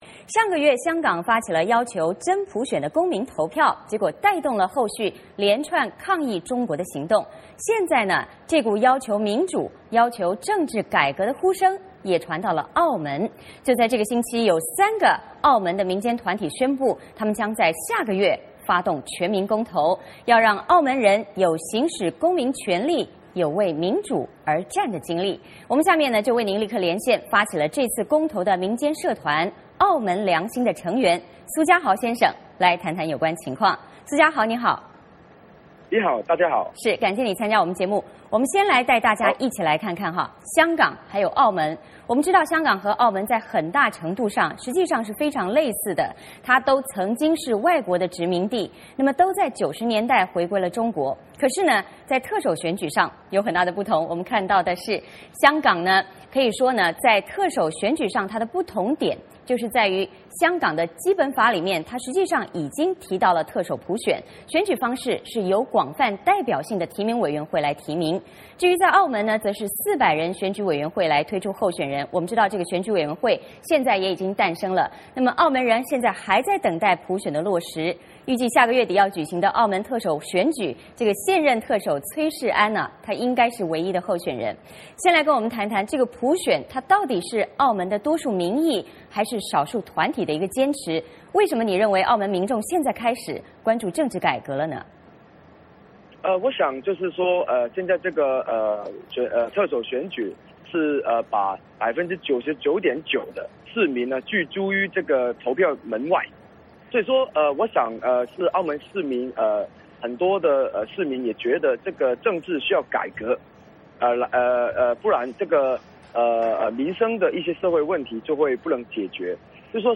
这个星期3个澳门民间团体宣布，将在下个月发动全民公投，让澳门人有行使公民权利，为民主而战的经历。我们请发起这次公投的民间社团“澳门良心”的成员苏嘉豪来谈谈有关情况。